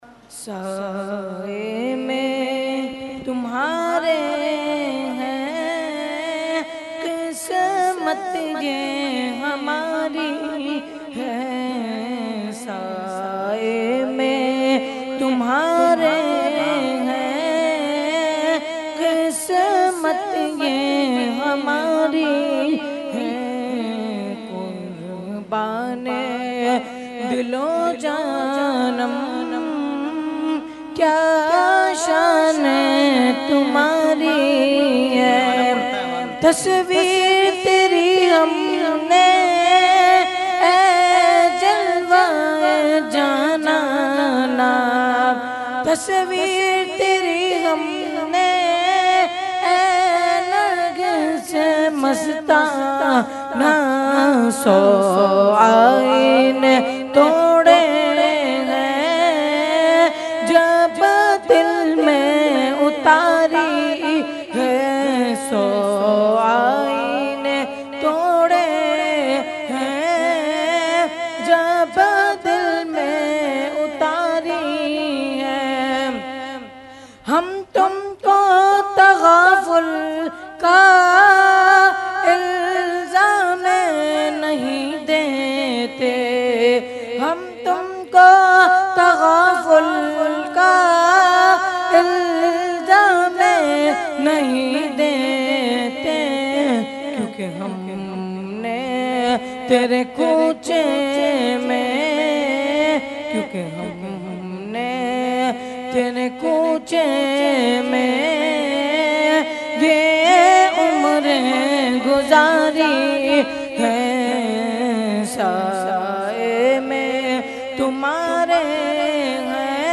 Category : Manqabat | Language : UrduEvent : Urs Ashraful Mashaikh 2019